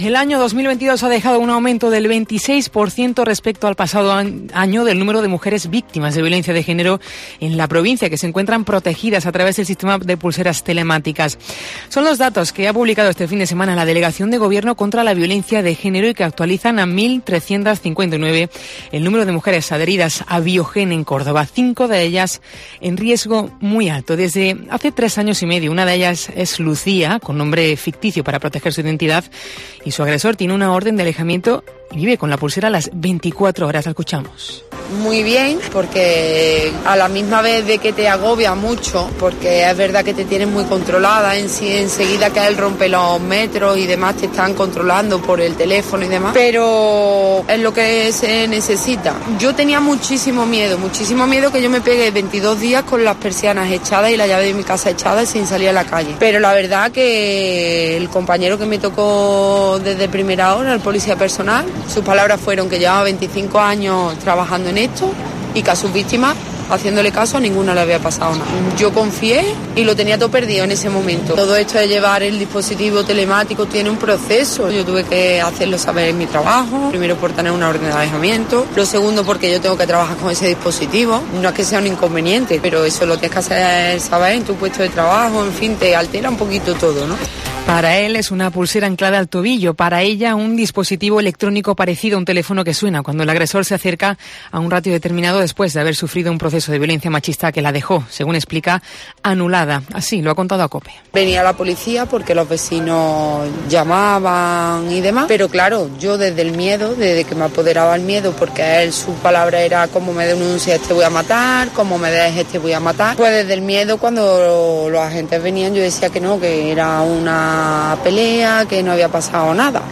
integrado dentro de VIOGÉN I Relata a COPE, en primera persona, su historia
"Mi hijo me miró ese dia y me dijo: "si tu quieres volver a casa hazlo, pero yo no. Ahí decidí que tenia que salir de esa mierda como fuera", expresa con la voz rota.